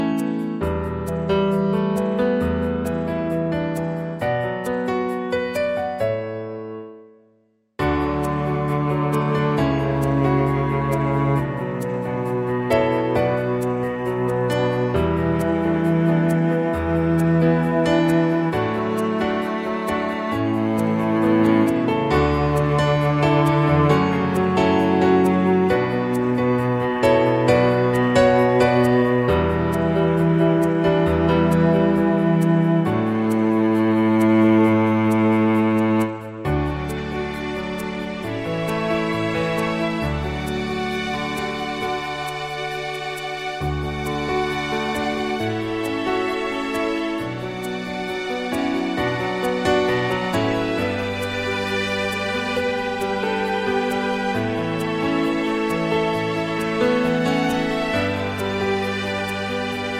+4 Female Key